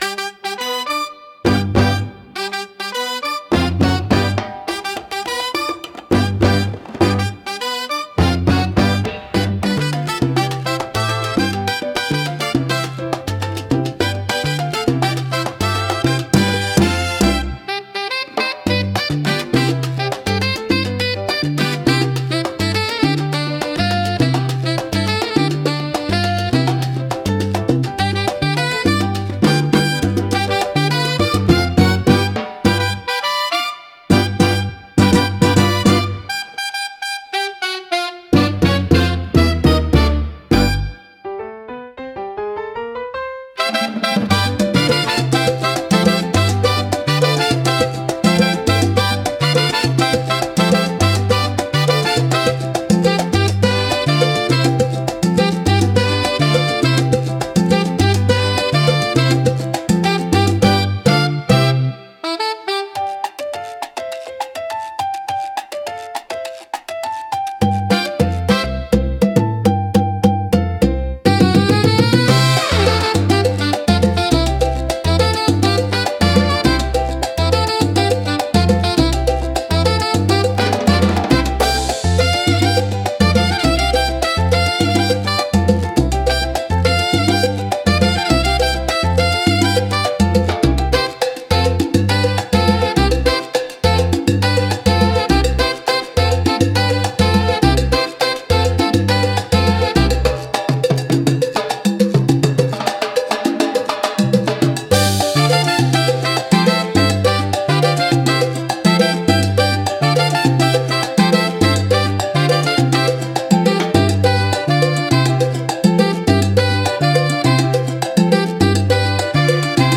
軽快なリズムとポップなメロディが楽しい雰囲気を作り出し、買い物やショッピング体験を爽やかに演出します。